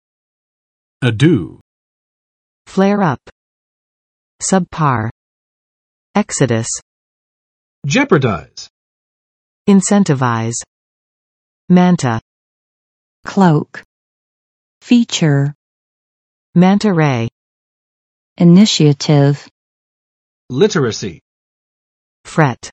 [əˋdu] n. 纷扰，骚扰；忙乱；麻烦，费力